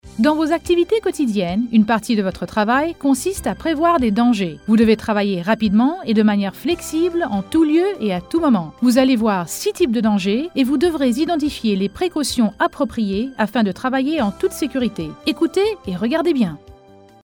Sprechprobe: eLearning (Muttersprache):
English and French Female Voice over artist for phone systems, corporate videos, radio and TV spots